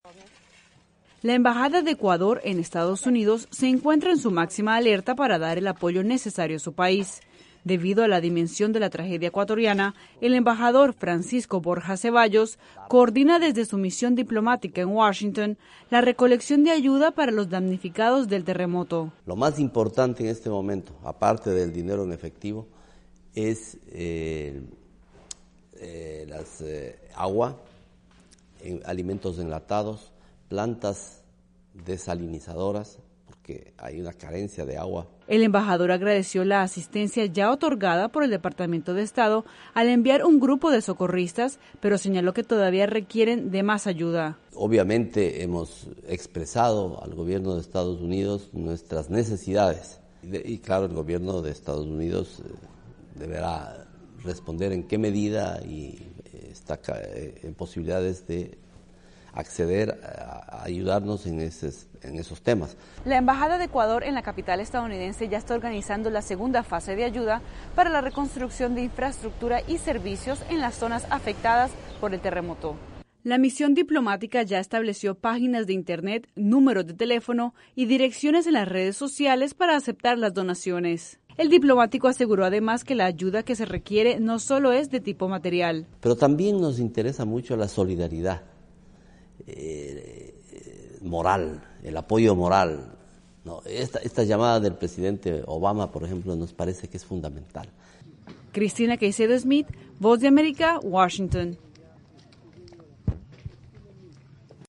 VOA: Entrevista con embajador ecuatoriano en Washington